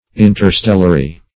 Interstellary \In`ter*stel"la*ry\, a.
interstellary.mp3